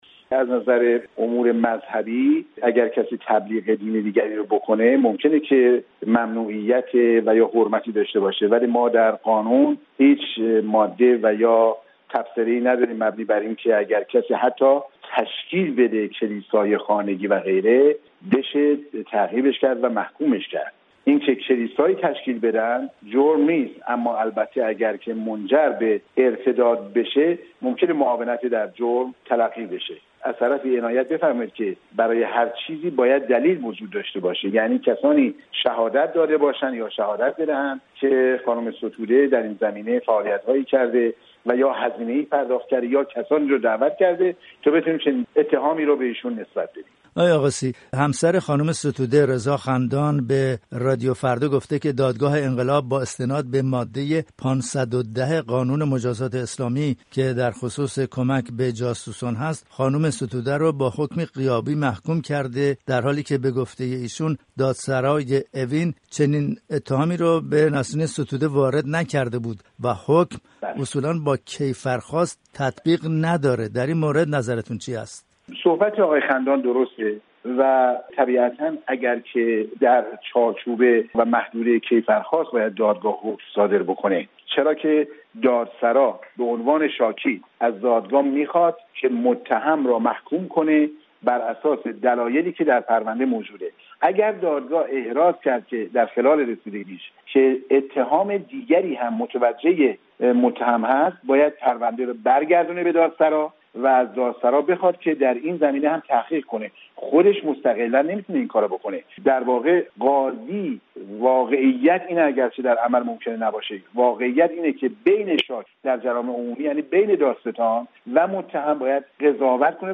در گفت‌وگو با